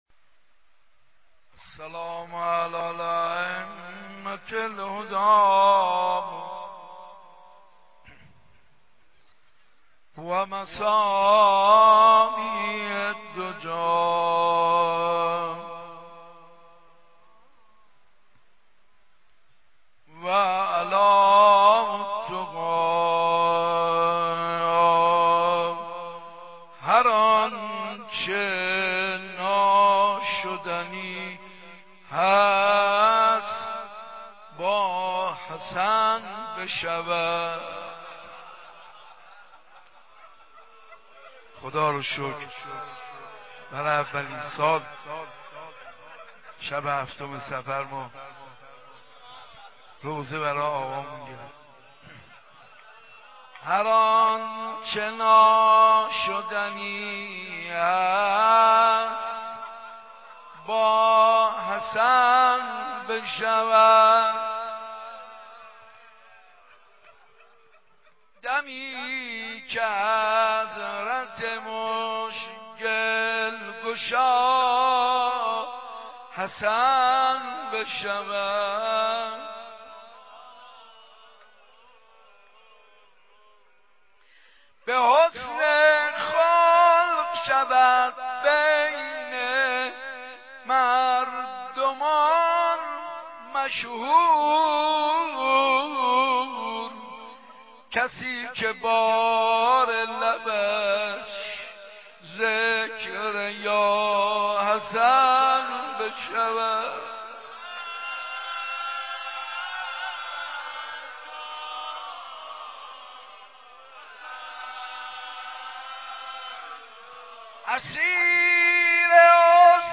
روضه امام حسن مجتبی